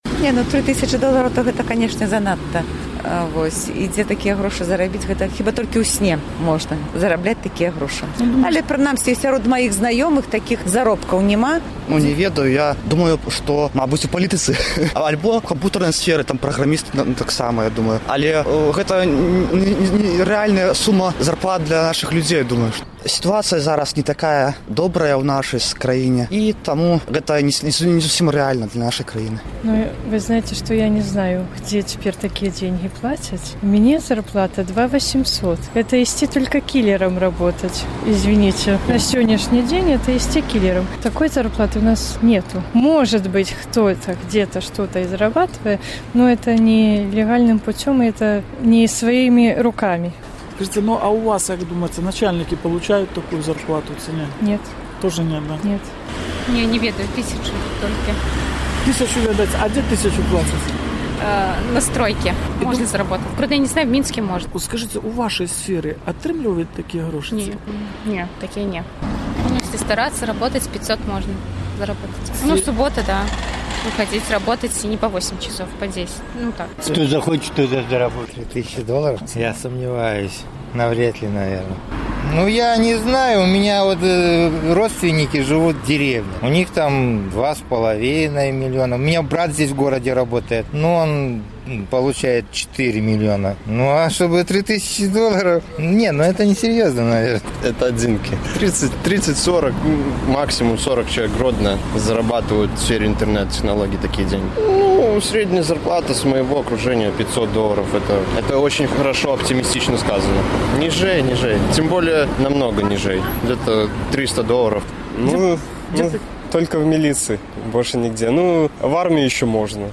На якой працы можна зарабляць 3000 даляраў? Разважаюць гарадзенцы
З такім пытаньнем наш карэспандэнт зьвяртаўся да гарадзенцаў.